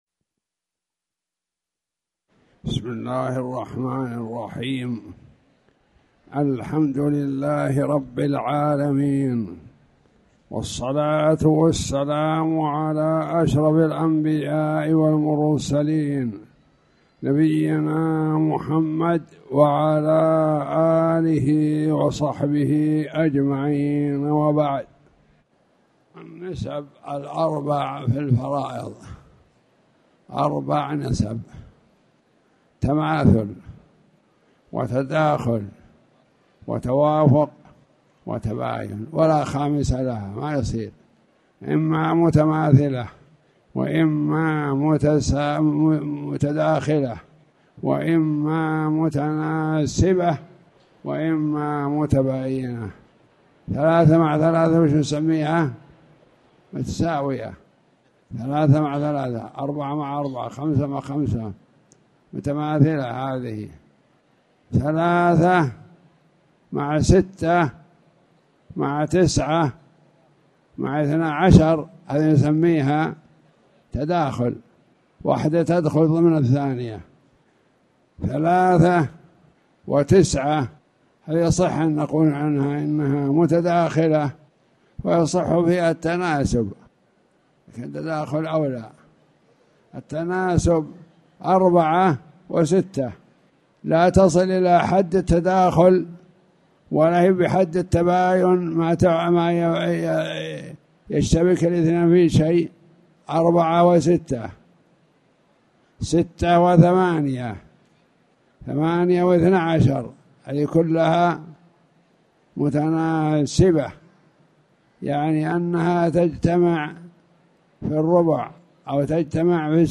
تاريخ النشر ٩ ذو القعدة ١٤٣٨ هـ المكان: المسجد الحرام الشيخ